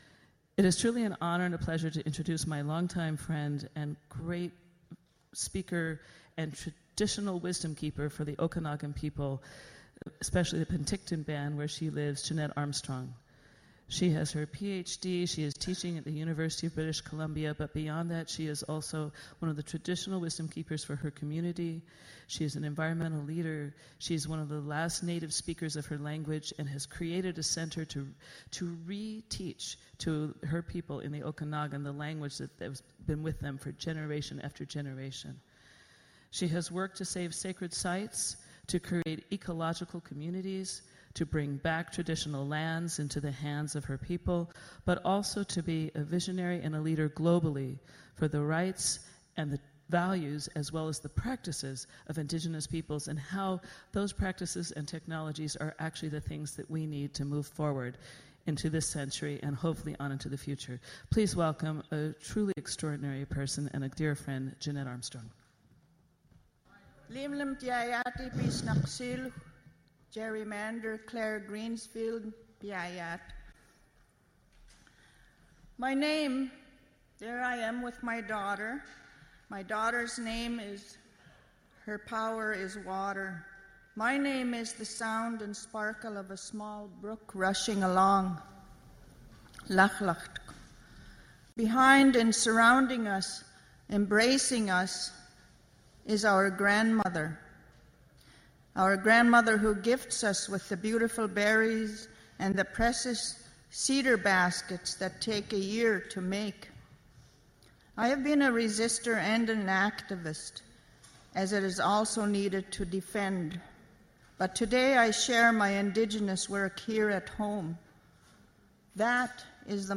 This presentation of Jeannette Armstrong was recorded at the International Forum on Globalization ’s Techno-Utopianism & The Fate Of The Earth Teach-In held in New York City on October 25-26, 2014.